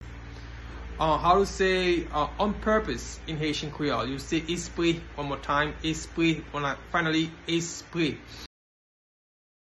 Listen to and watch “Espre” pronunciation in Haitian Creole by a native Haitian  in the video below:
On-purpose-in-Haitian-Creole-Espre-pronunciation-by-a-Haitian-tutor.mp3